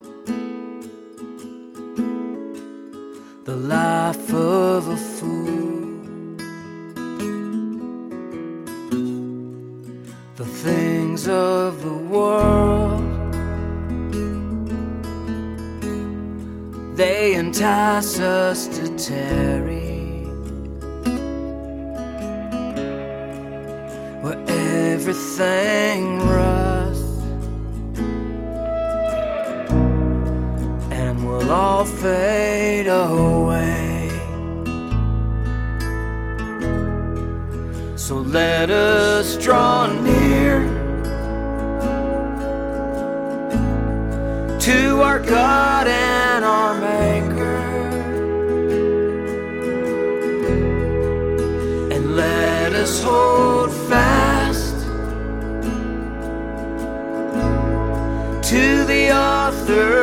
zeitgemäße, gemeindetaugliche Lobpreismusik
• Sachgebiet: Praise & Worship